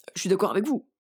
VO_ALL_Interjection_18.ogg